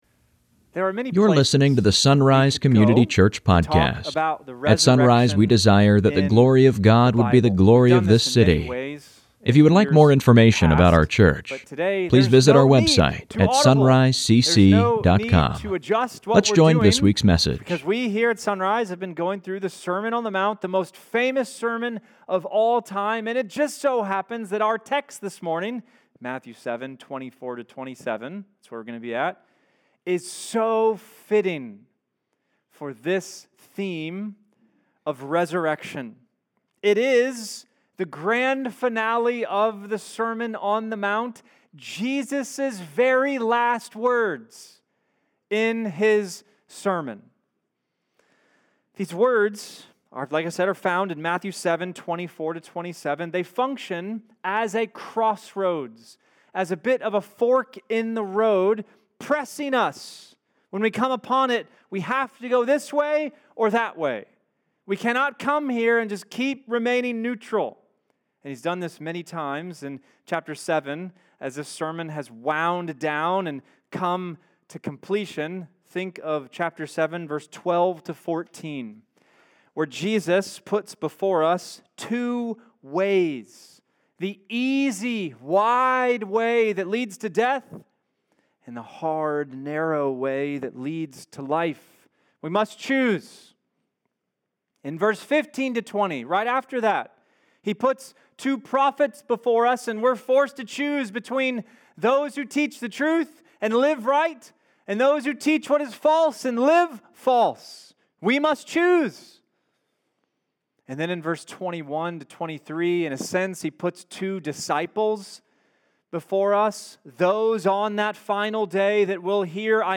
Sunday Mornings | SonRise Community Church